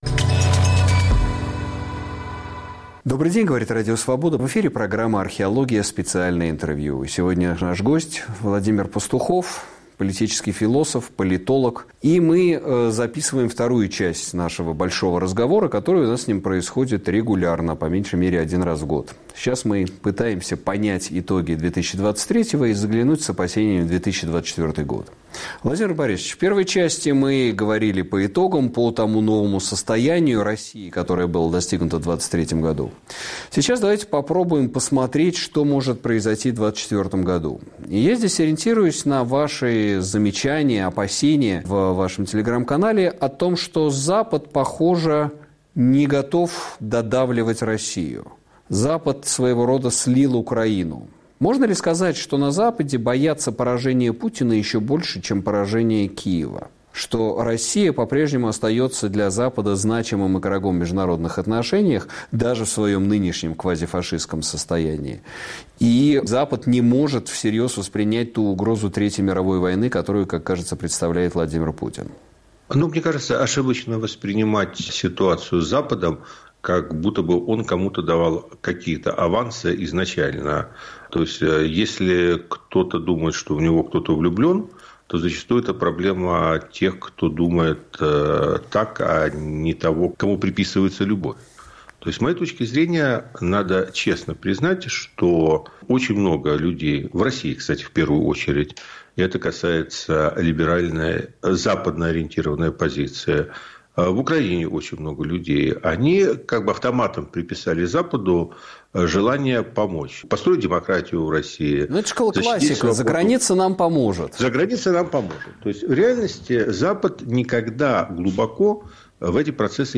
На вопросы Сергея Медведева отвечает политолог Владимир Пастухов